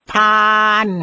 th “thaa”